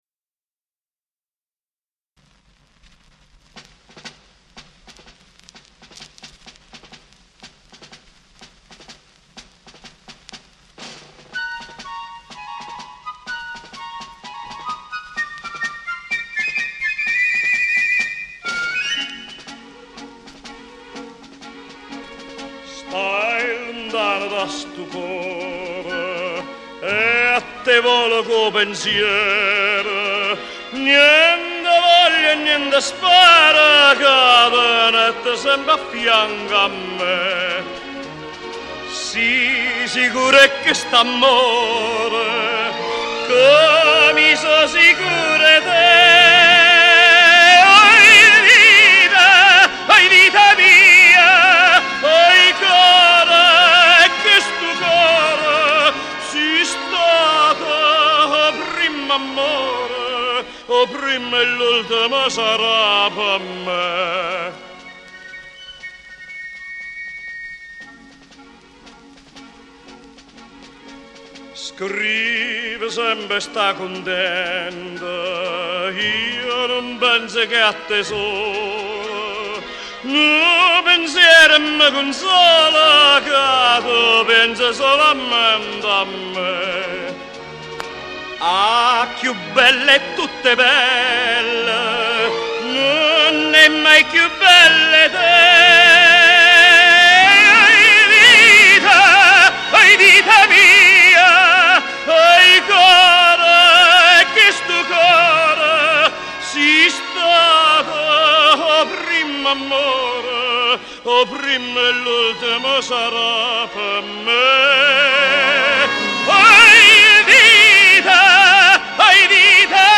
con accompagnamento orchestrale e coro